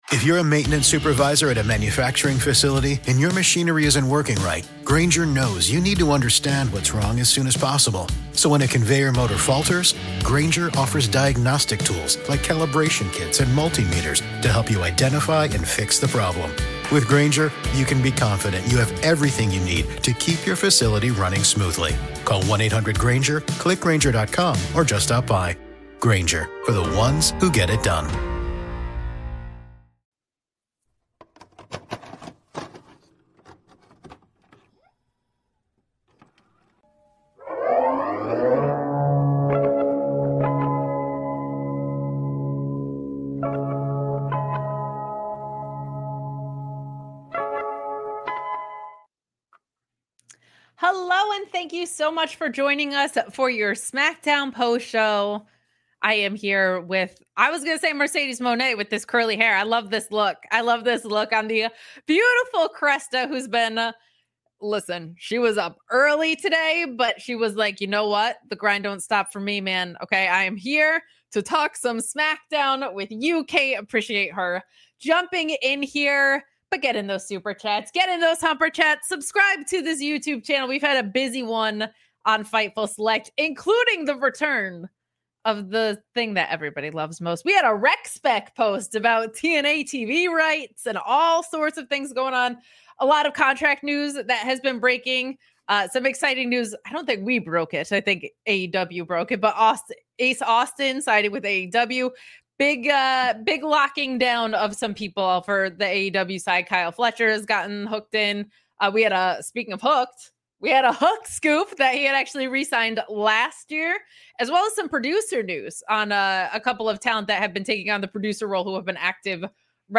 Download - Good Brothers Talk IMPACT Contracts, WWE, Japan, More | 2022 Interview | Podbean